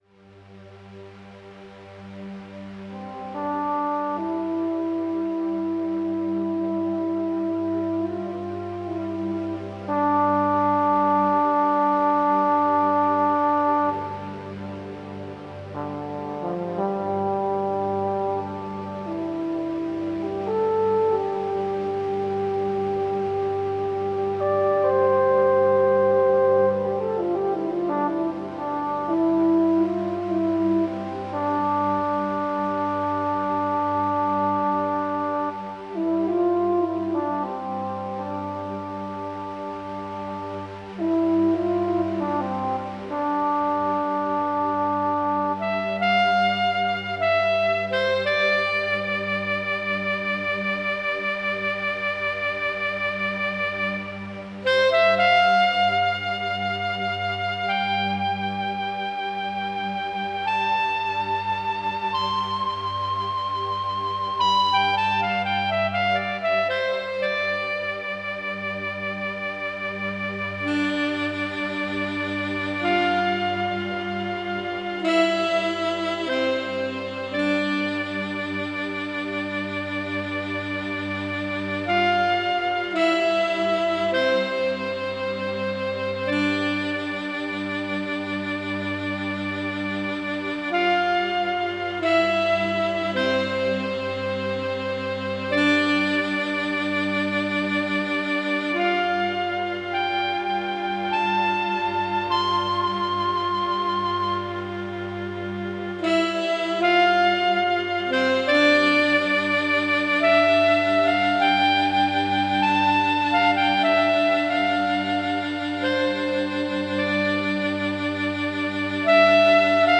Nur Intro